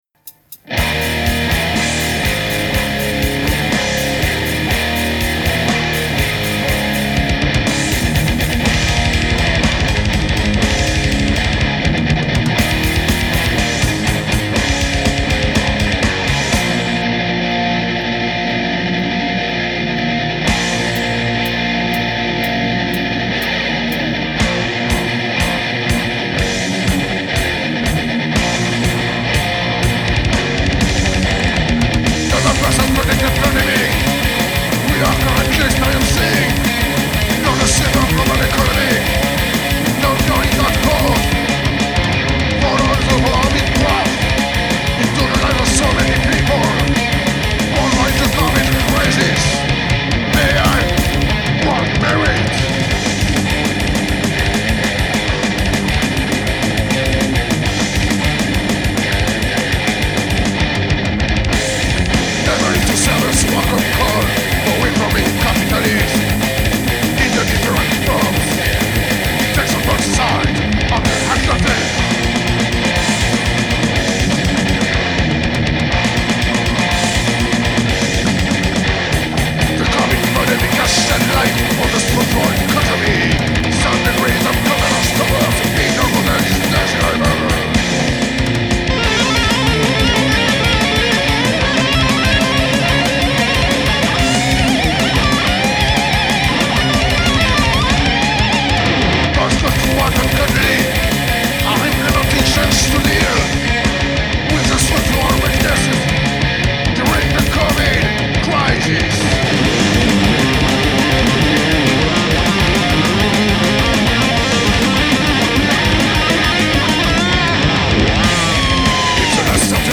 ---  THRASH-METAL GAULOIS - UN PROJET NÉ À SAMAROBRIVA ---